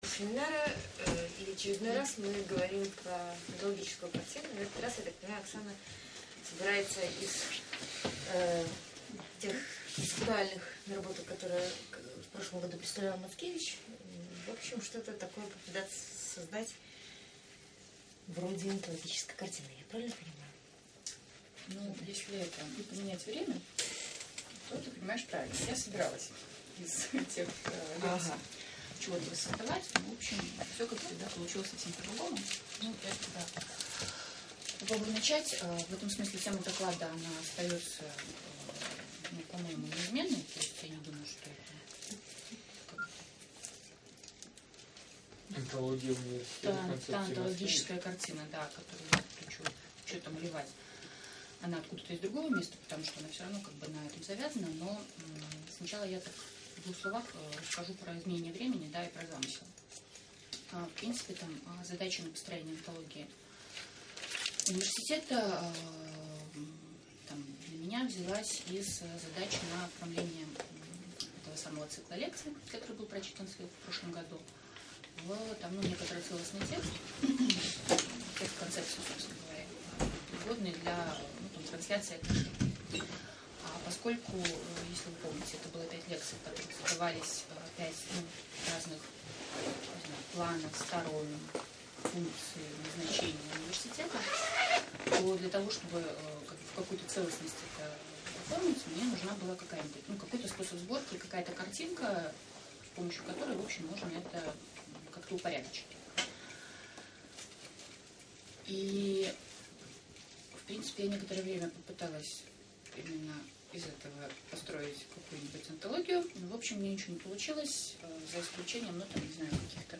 Аудиозаписи университетского семинара сезона 2010/2011